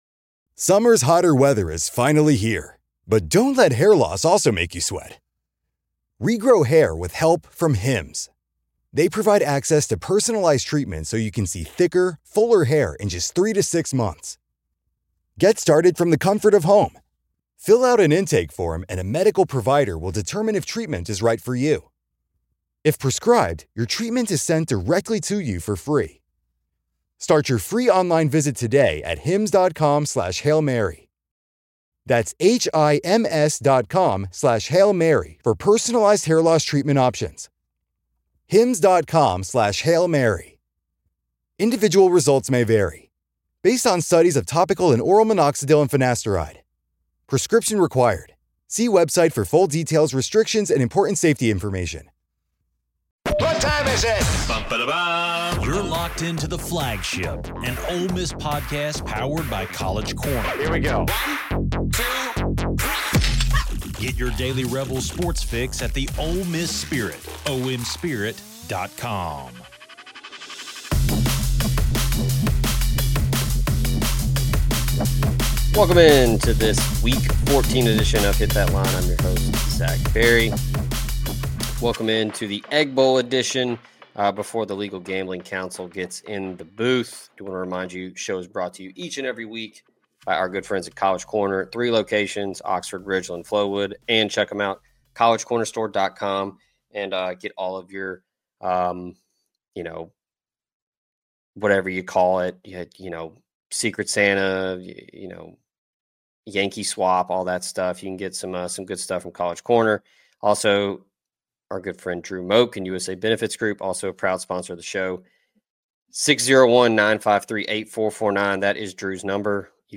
But first, the guys have some gripes (read: YELLING) to hash out about the College Football Playoff rankings. They also preview Ole Miss’ Egg Bowl matchup with in-state rival Mississippi State and pick winners.